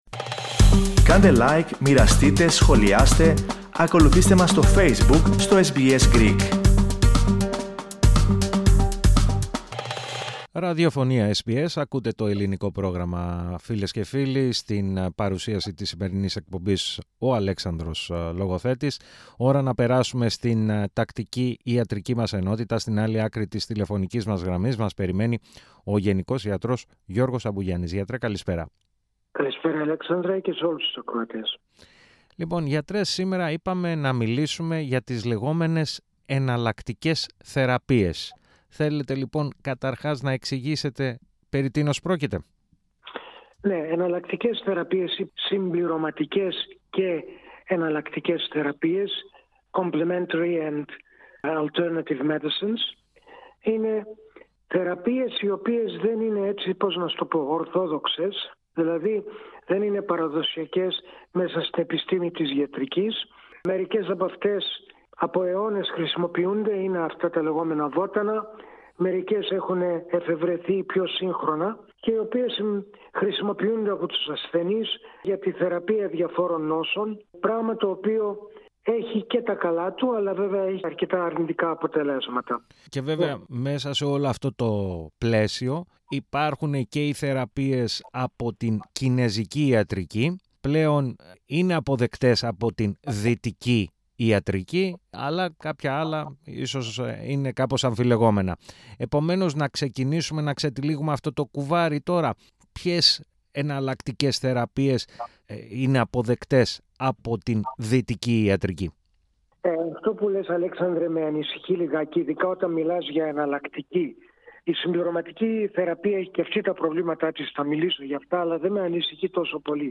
Ο γενικός ιατρός